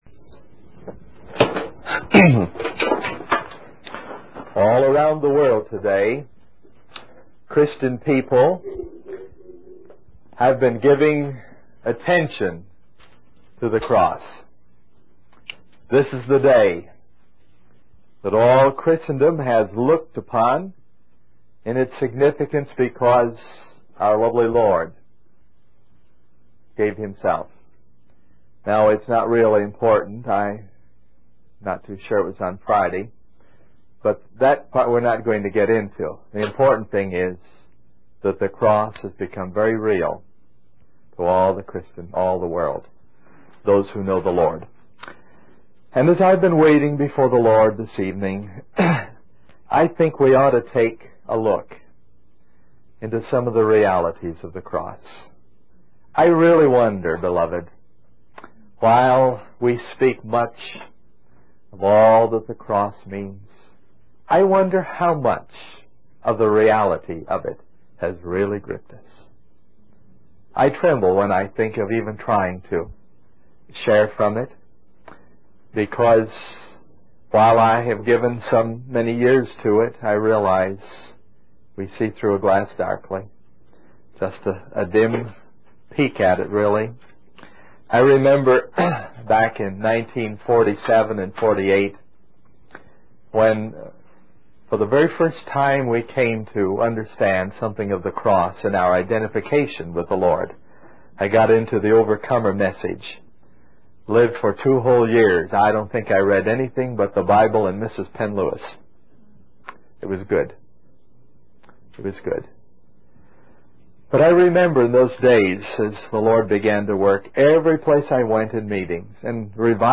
In this sermon, the speaker shares a personal experience about the importance of giving thanks in all circumstances.